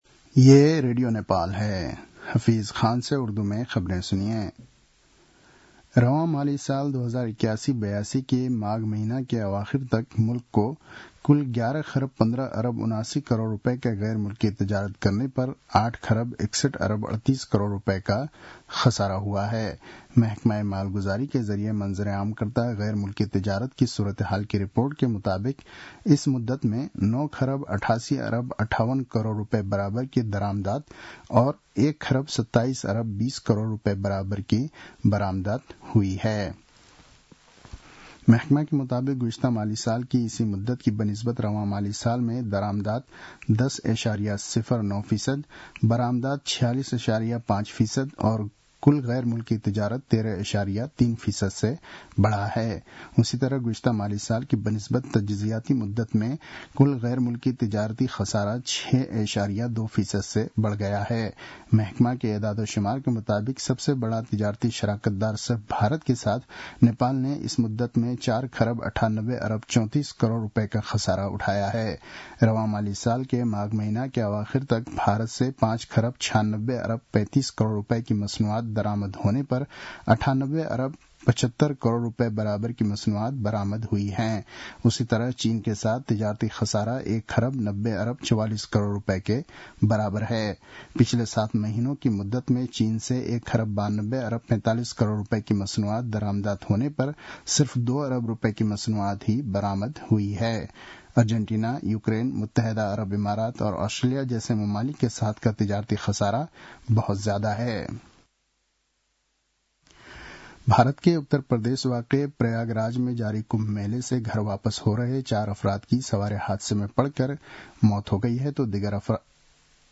उर्दु भाषामा समाचार : ११ फागुन , २०८१